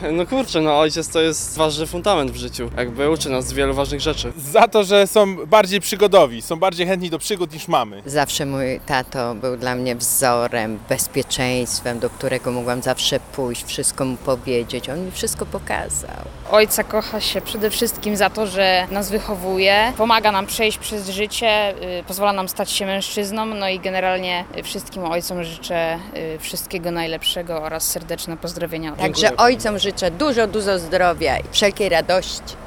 Dzień Ojca [SONDA]
Zielonogórzanie składają życzenia wszystkim ojcom.